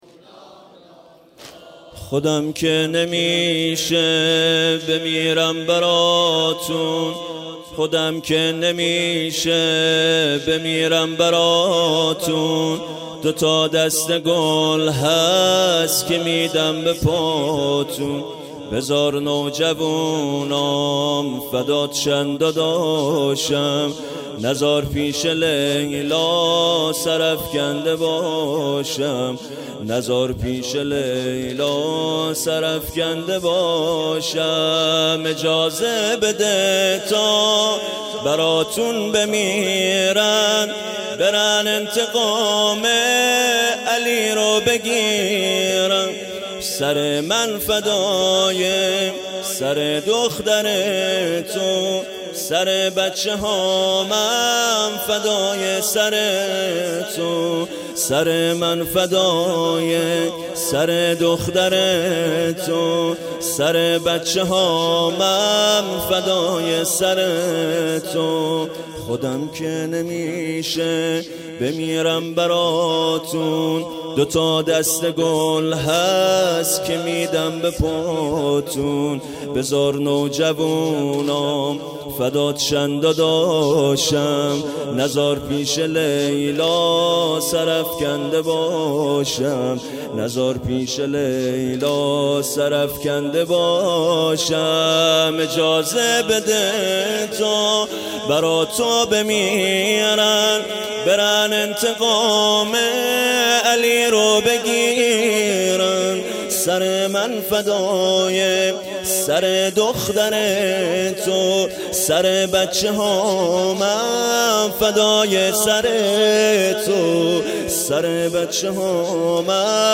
محرم 97 شب چهارم